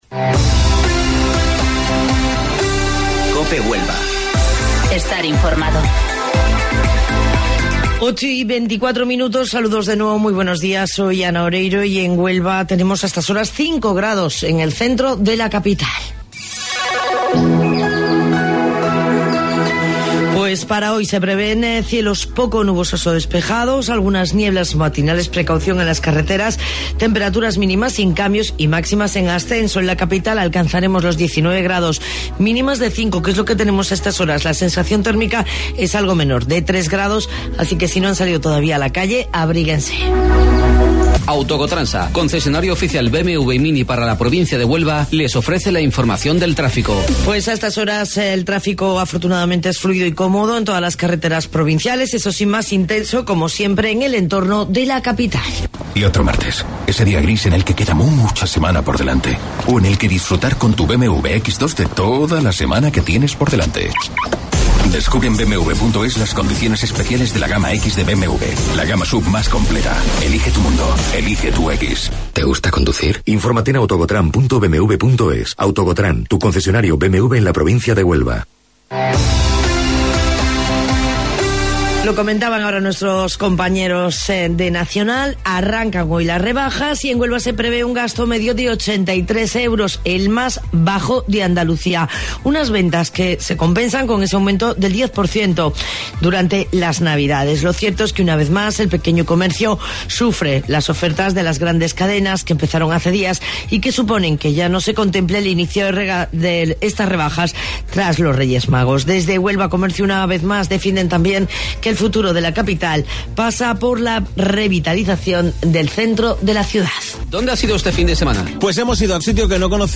AUDIO: Informativo Local 08:25 del 7 de Enero